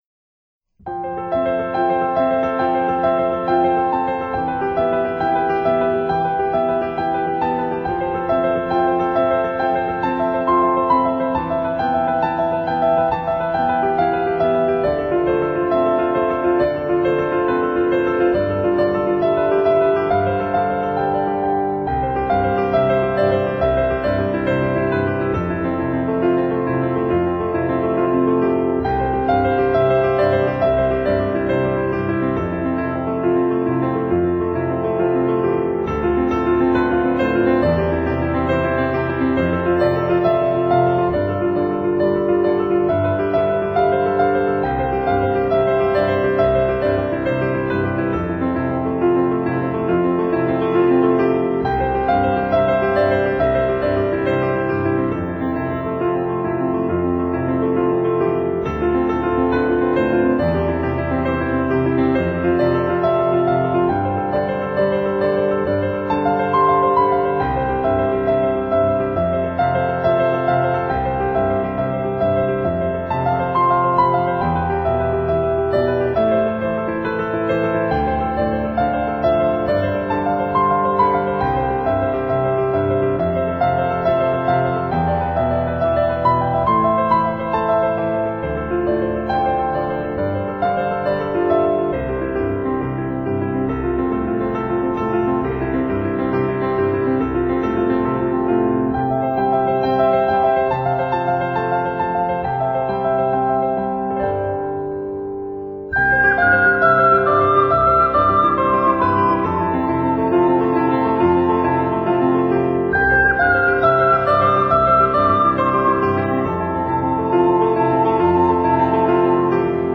活化自然界「1/F搖晃」能量波動的專業音樂輯